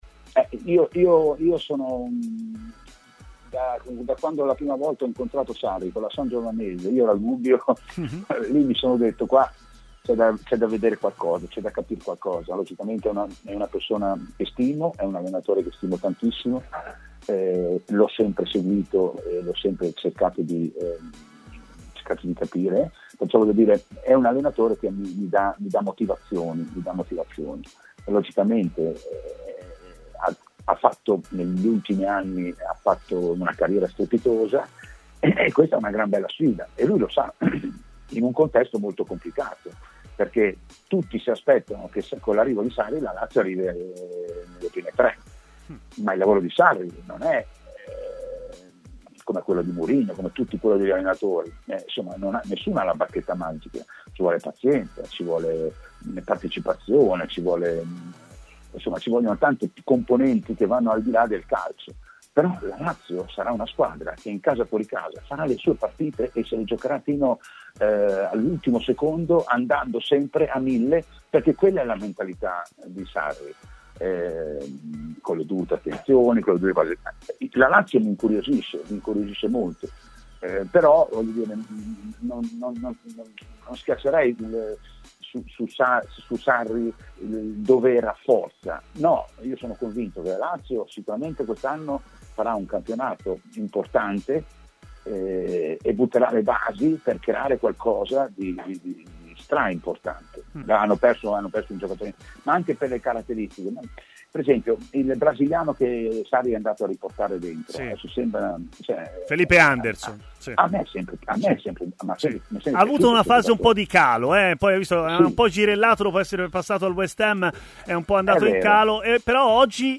Intervenuto ai microfoni di TMW Radio, Giuseppe Galderisi ha parlato anche della Lazio e, in particolare, di Maurizio Sarri.